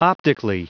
Prononciation du mot optically en anglais (fichier audio)
Prononciation du mot : optically